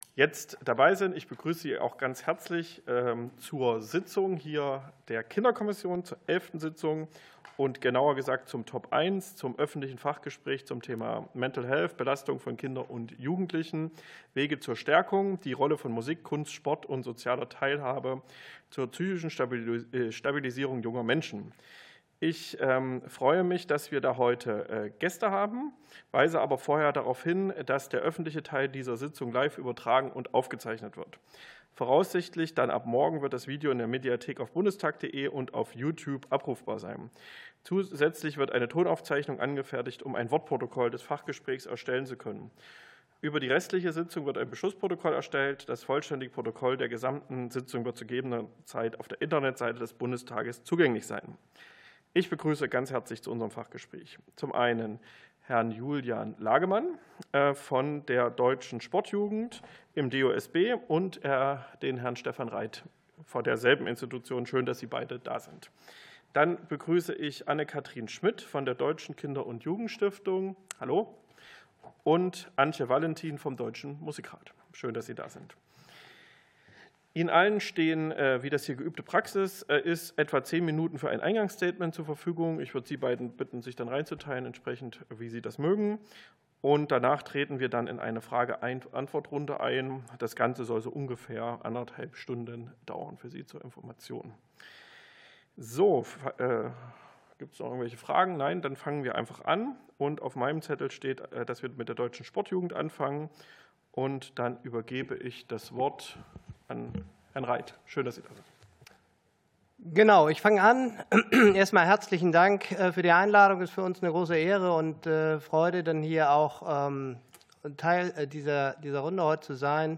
Fachgespräch der Kinderkommission